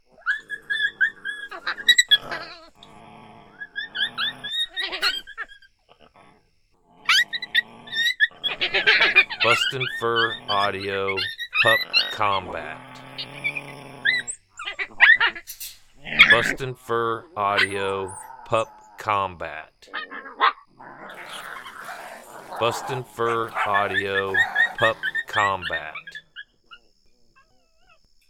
Aggressive Coyote Pup fight. Very successful sound for calling Coyotes.
BFA Pup Combat Sample.mp3